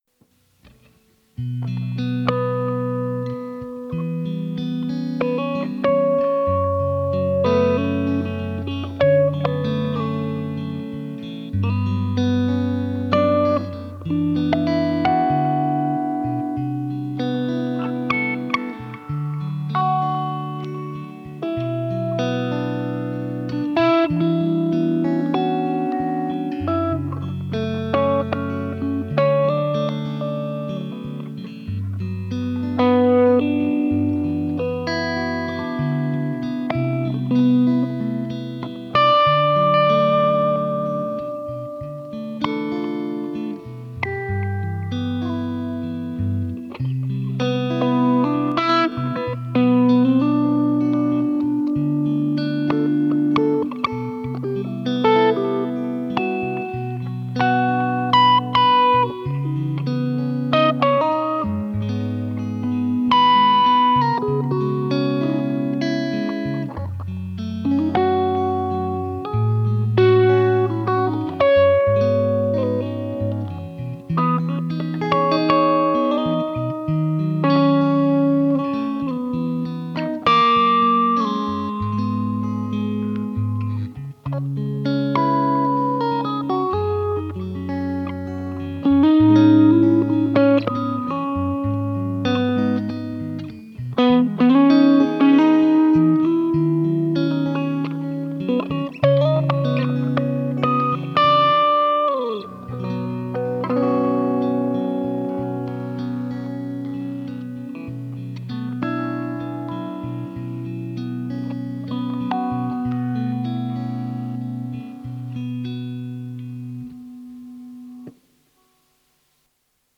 cover 2)